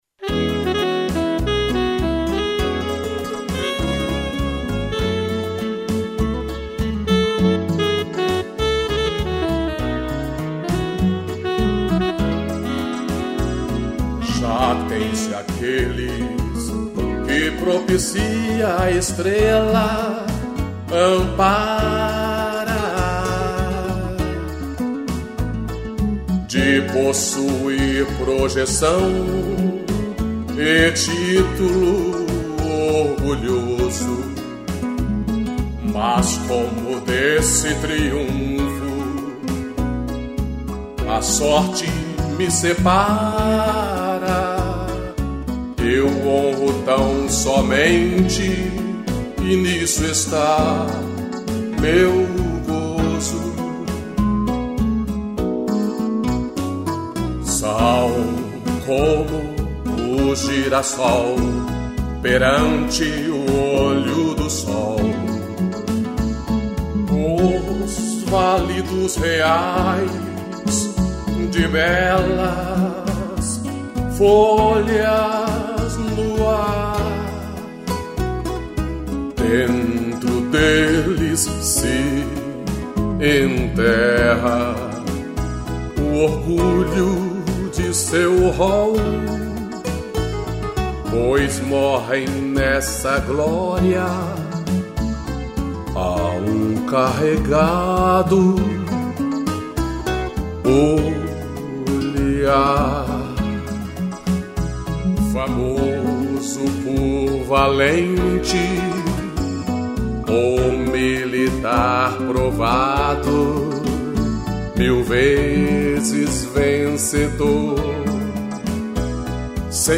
interpretação e violão